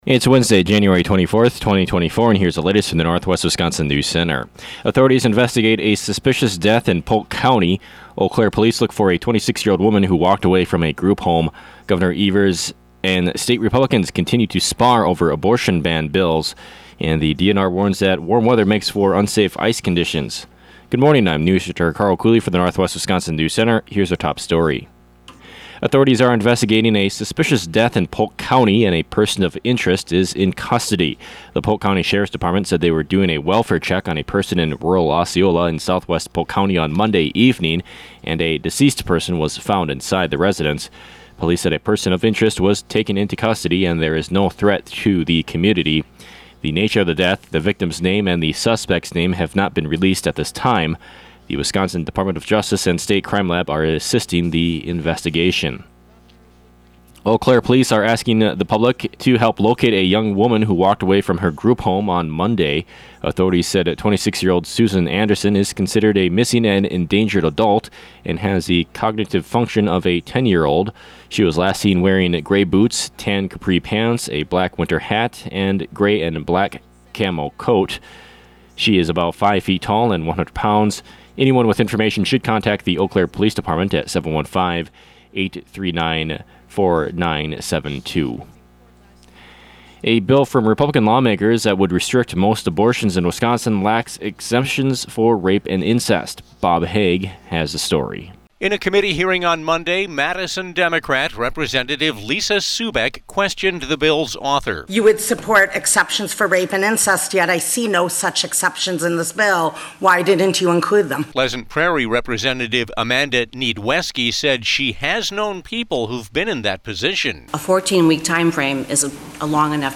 AM NEWSCAST – Wednesday, Jan. 24, 2024